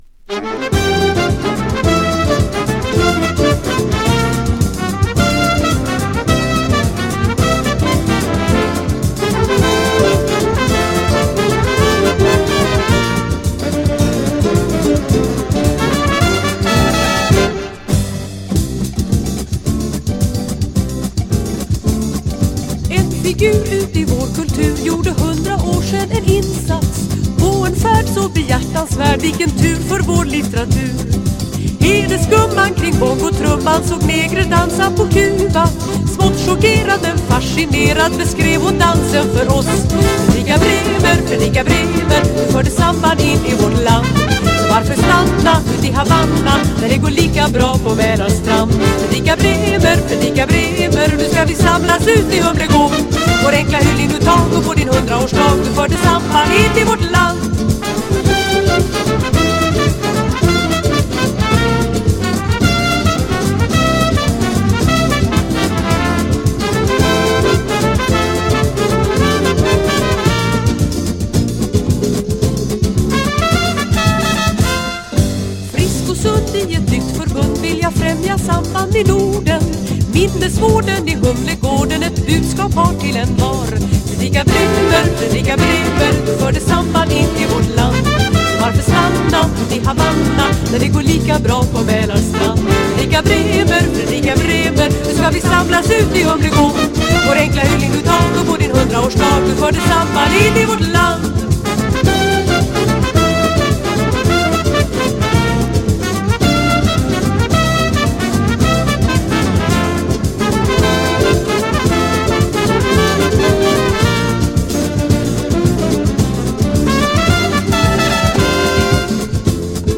Bossa / Samba sweden
軽やかなギターに心地よく鳴り響くブラスも爽快なアコースティック・サンバ。